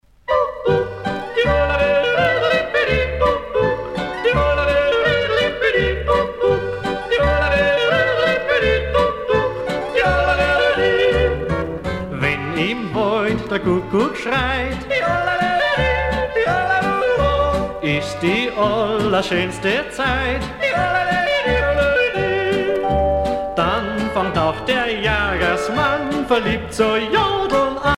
danse : fox-trot
Pièce musicale éditée